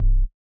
ORG Bass C0.wav